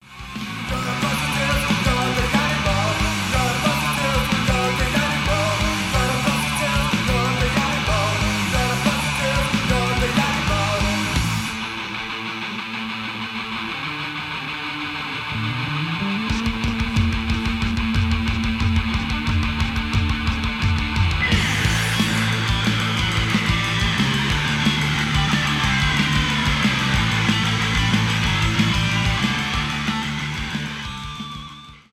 панк-рок , рок